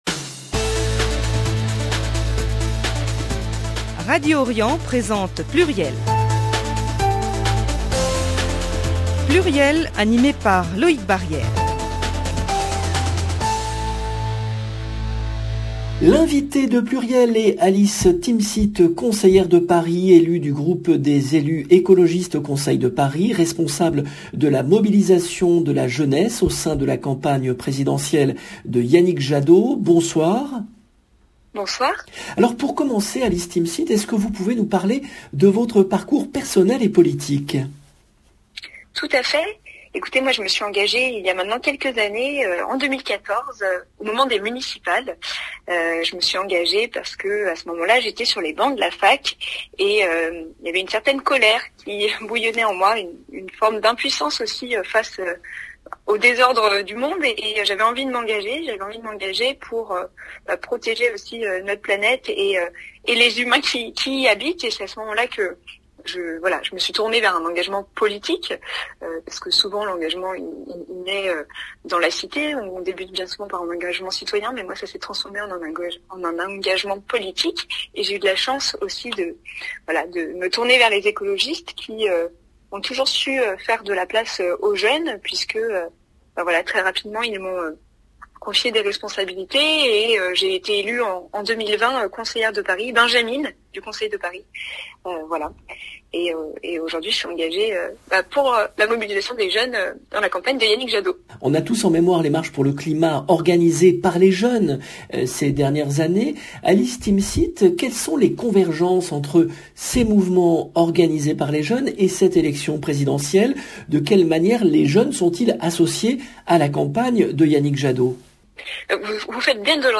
L’invitée de PLURIEL est Alice Timsit , conseillère de Paris, membre du Groupe écologiste au Conseil de Paris, responsable de la mobilisation de la jeunesse au sein de la campagne présidentielle de Yannick Jadot